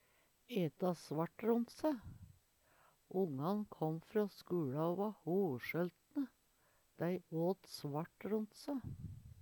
eta svart ront se - Numedalsmål (en-US)